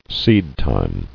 [seed·time]